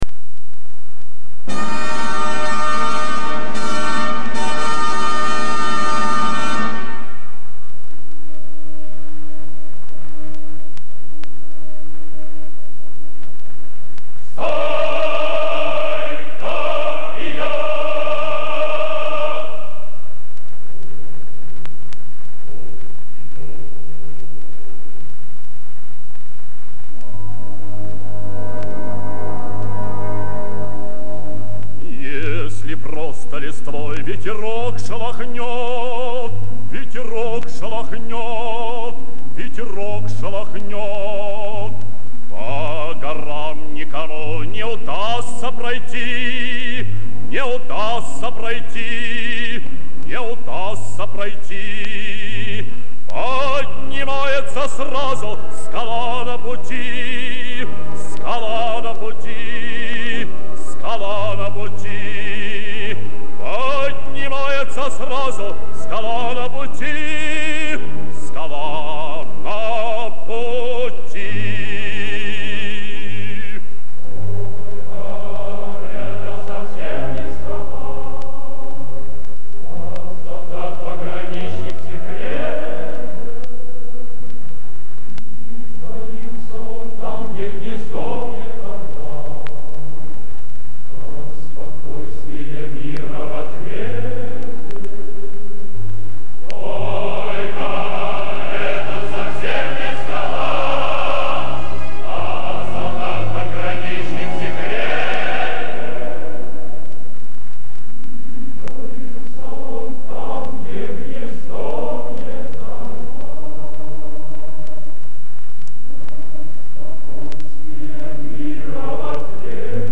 Описание: Забавная необычная песня про крутых пограничников